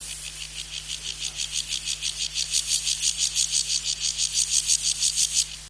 Акустические сигналы саранчового
Акустические сигналы: одиночный самец, Россия, Тува, Эрзинский район, запись
Температура записи 27-29° С.